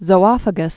(zō-ŏfə-gəs)